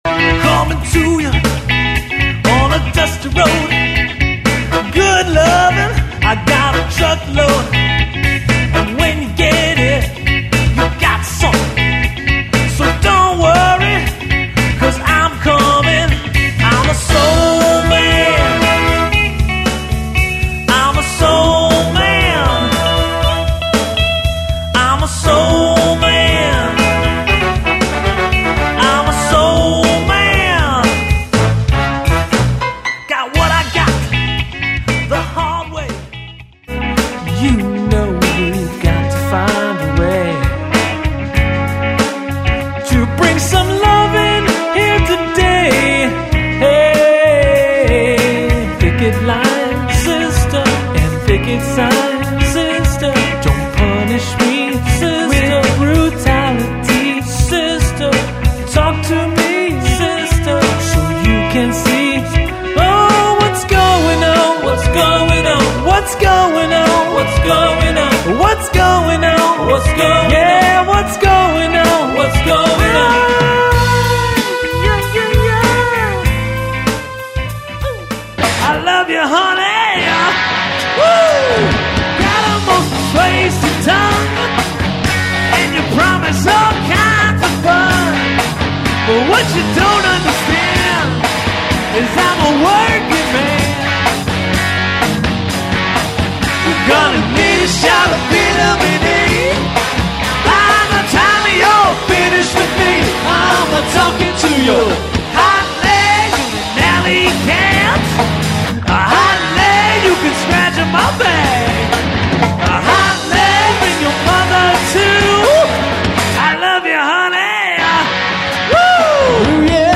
with me singing and playing guitar
with me singing all vocals and playing guitar
with me singing and playing bass